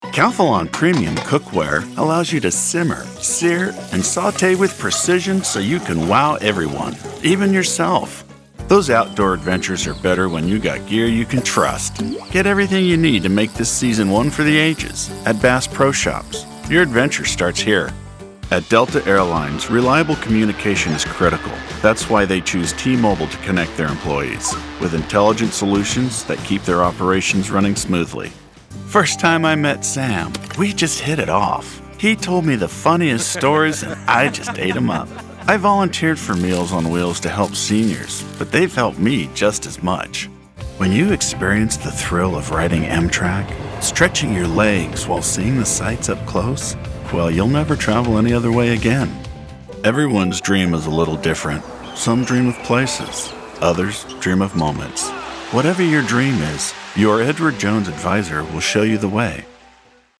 commercial / Narration
I can offer a voice that can be smooth and laid back, to gruff and raspy.
Mostly standard American English with some variations in dialect. American western, standard southern accent, as well as standard British English.